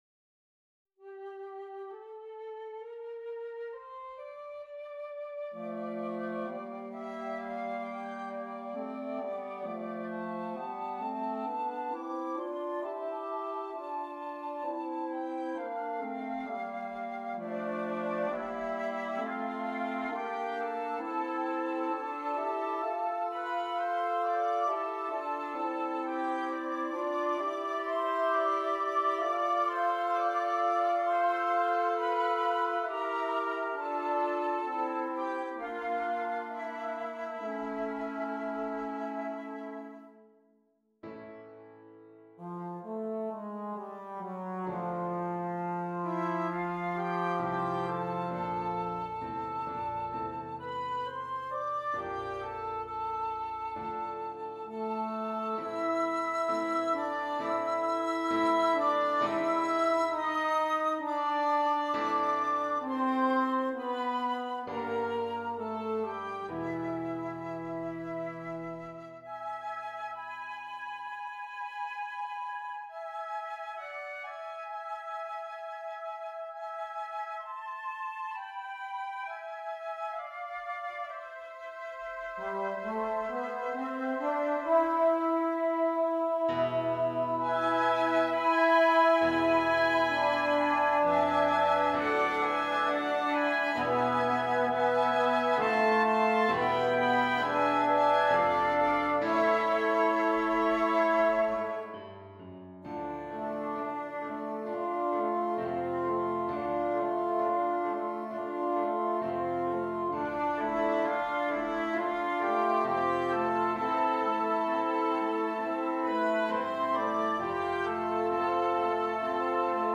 Woodwind Quintet and Piano